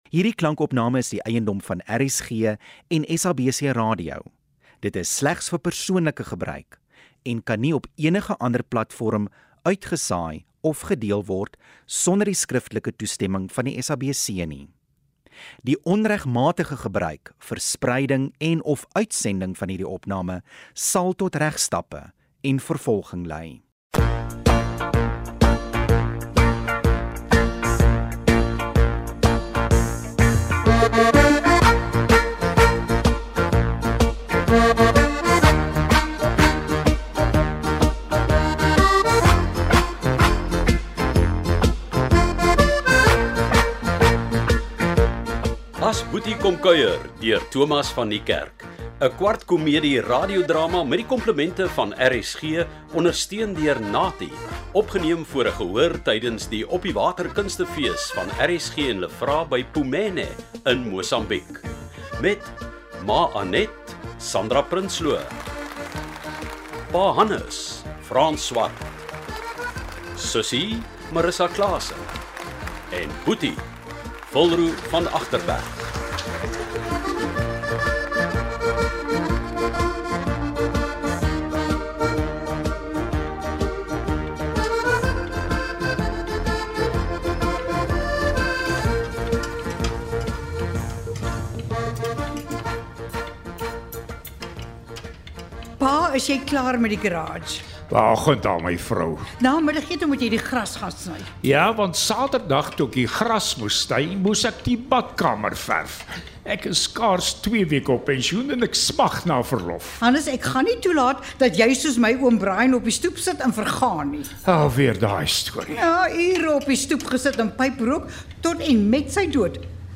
‘n Spesiale ervaring wag op Donderdag 9 Junie op luisteraars wanneer twee dramas in Radioteater uitgesaai word.
Albei is voor ‘n gehoor opgeneem tydens vanjaar se Oppiwater Kunstefeeste.